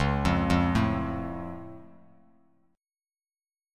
Listen to C#+ strummed